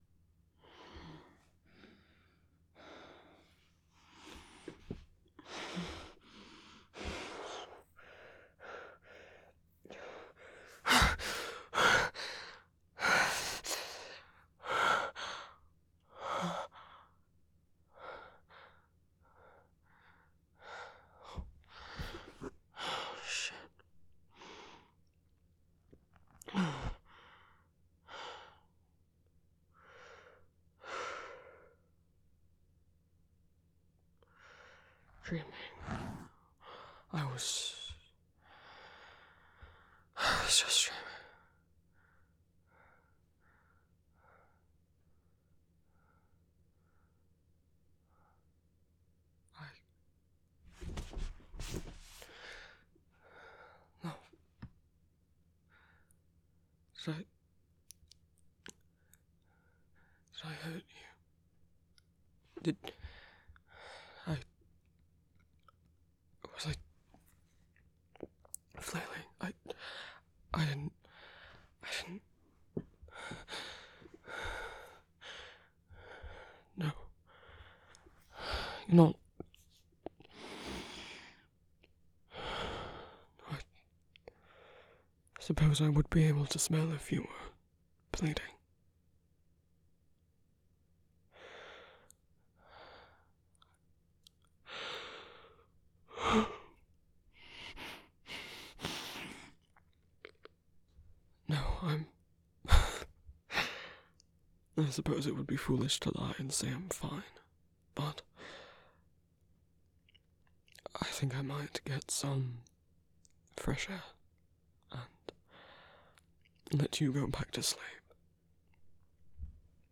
[Reverse nightmare comfort]